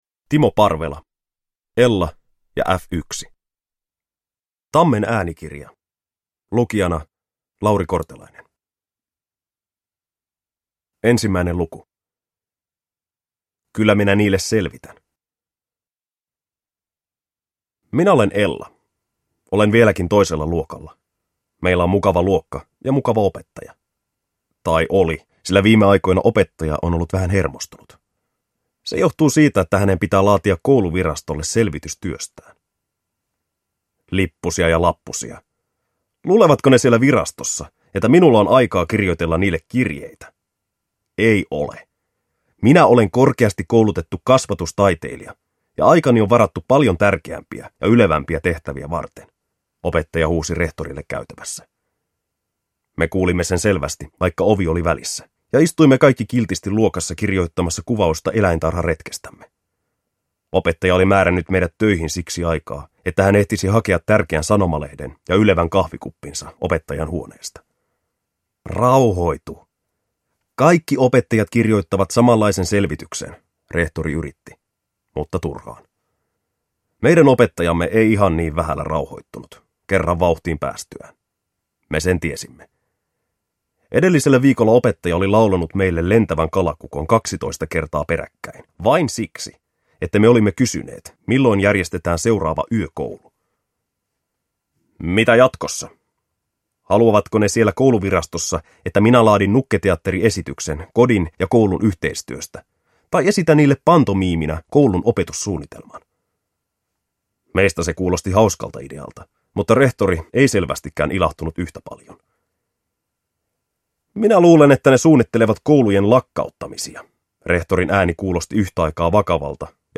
Ella ja Äf Yksi (ljudbok) av Timo Parvela